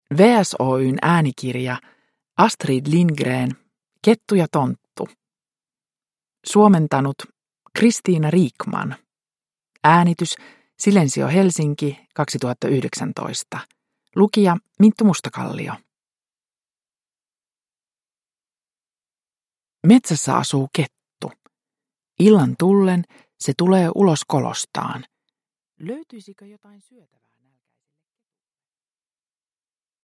Kettu ja tonttu – Ljudbok – Laddas ner